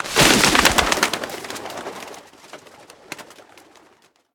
birdsfear2.ogg